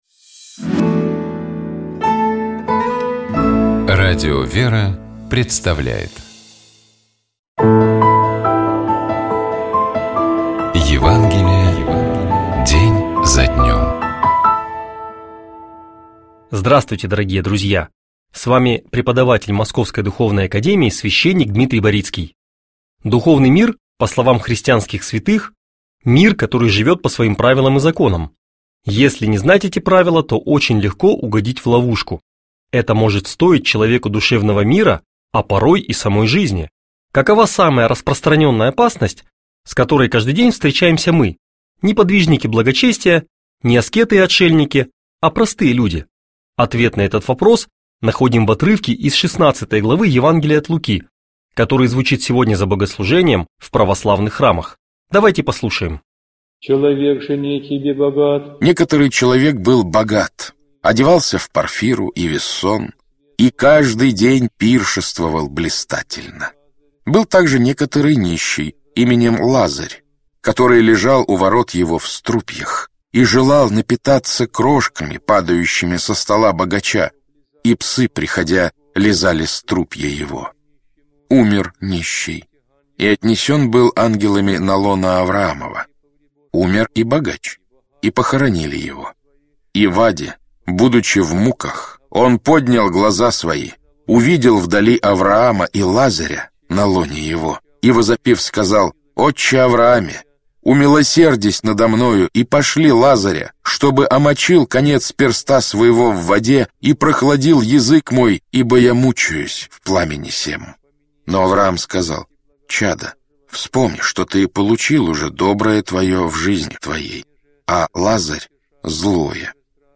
епископ Феоктист ИгумновЧитает и комментирует епископ Переславский и Угличский Феоктист